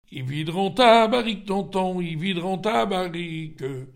Mémoires et Patrimoines vivants - RaddO est une base de données d'archives iconographiques et sonores.
circonstance : bachique ; circonstance : fiançaille, noce ;
Pièce musicale inédite